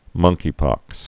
(mŭngkē-pŏks)